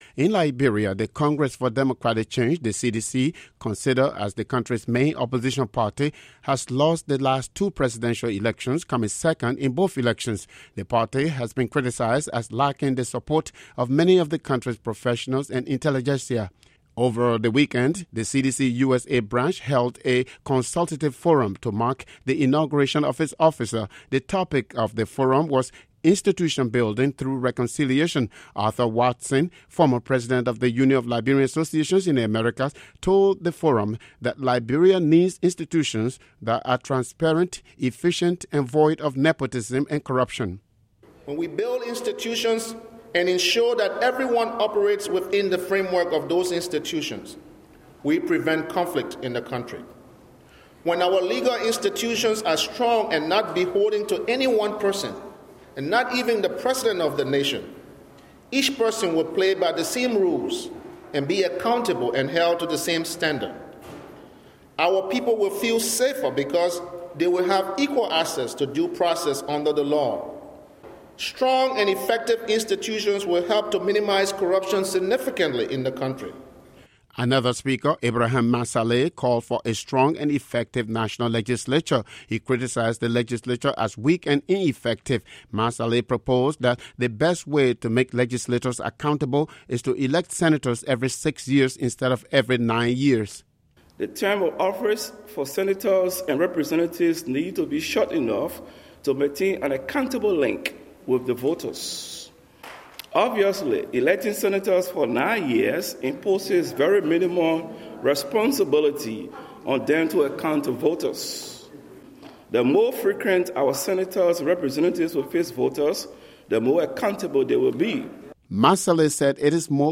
CDC-USA Institutional Building Panel
Over the weekend, the CDC-USA branch held a consultative forum to mark the inauguration of its officers.
CDC Institutional Building Forum